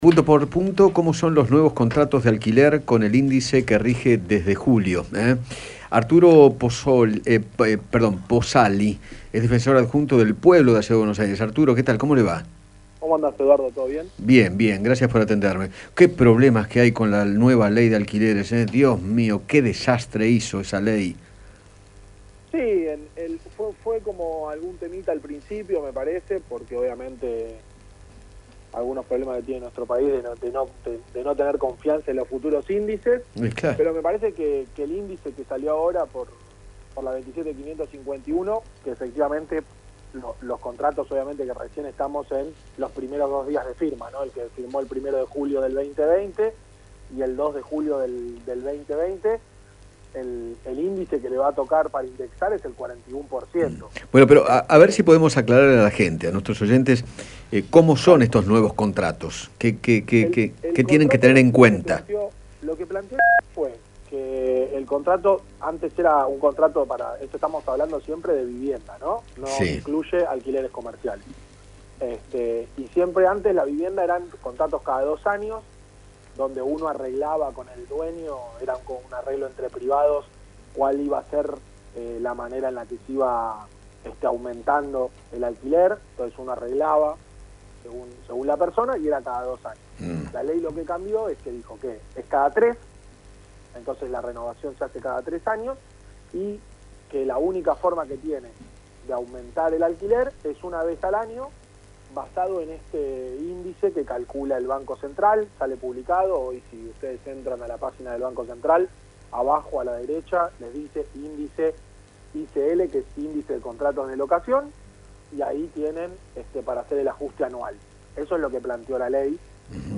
Arturo Pozzali, defensor adjunto del Pueblo de la Ciudad de Buenos Aires, conversó con Eduardo Feinmann acerca de las modificaciones que genera la Ley de Alquileres en los contratos firmados a partir del 1 de julio del 2020.